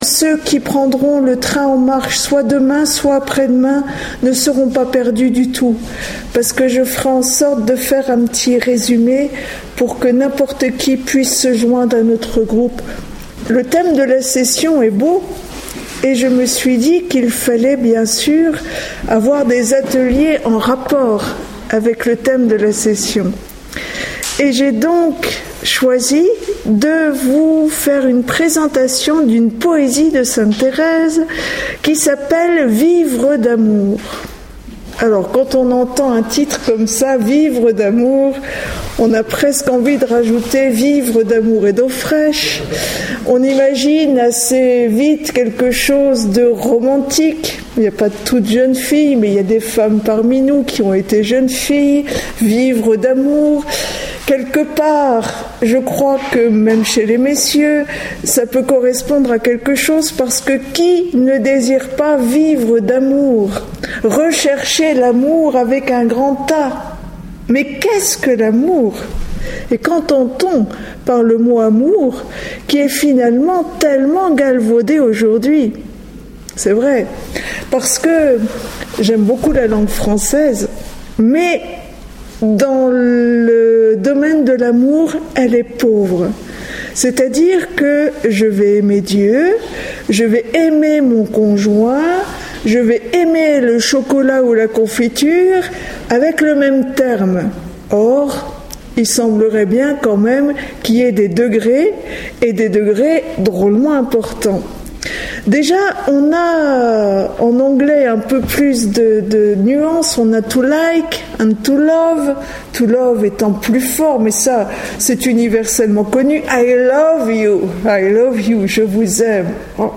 Enseignement
Atelier : à l'école de Thérèse, (Session Lisieux août 2012)
Enregistré en 2012 (Session des Béatitudes - Lisieux 2012)